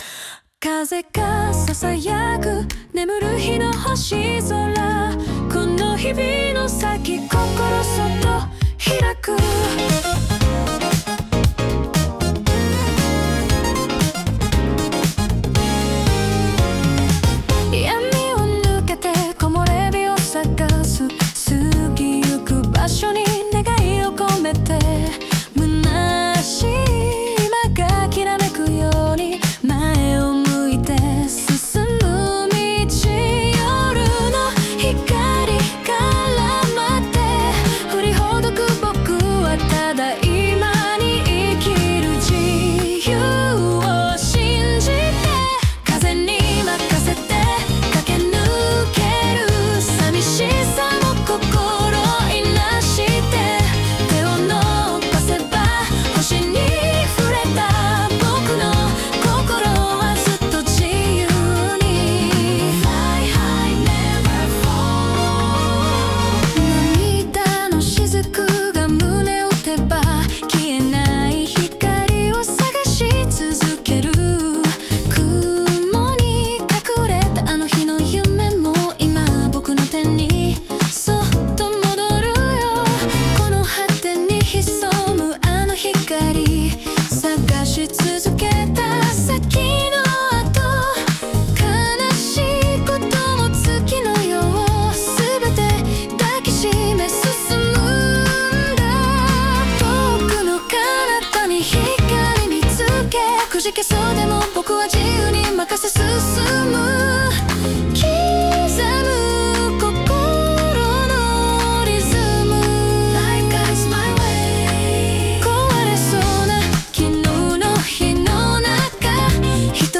イメージ：シティーPOP,８０年代,女性ボーカル,フューチャーファンク